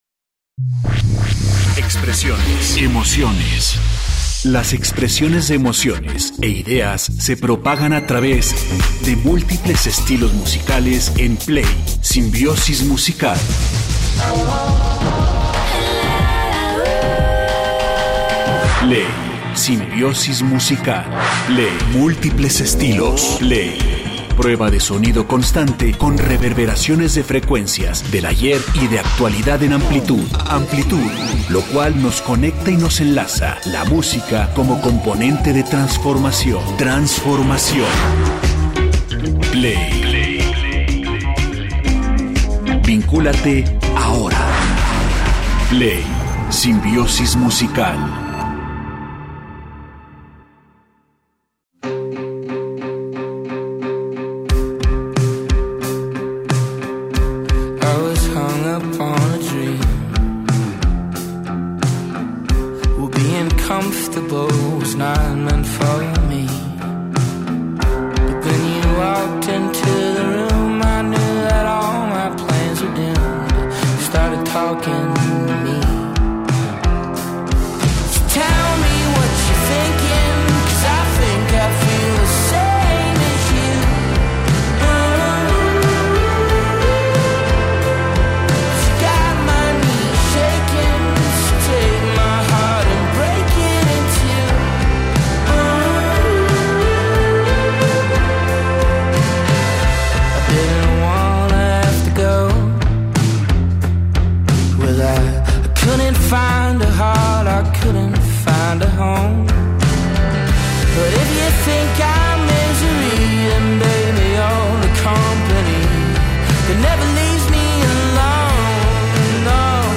la amplia seleccio´n musical